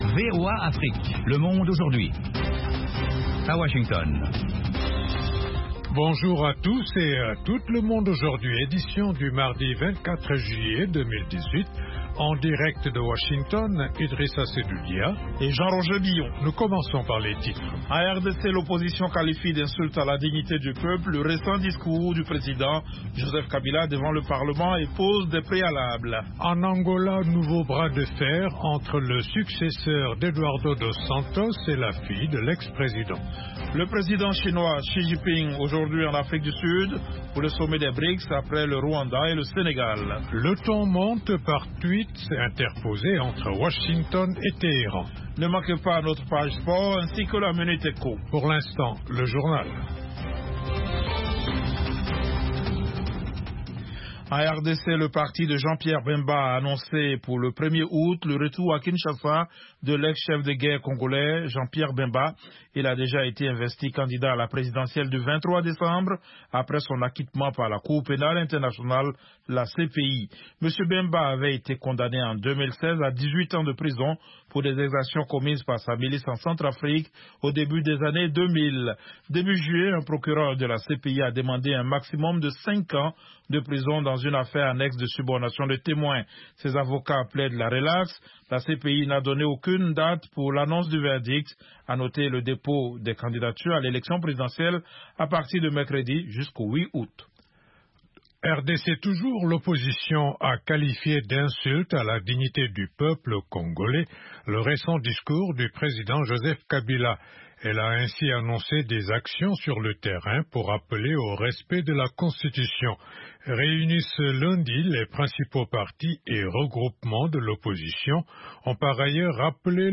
Toute l’actualité sous-régionale sous la forme de reportages et d’interviews.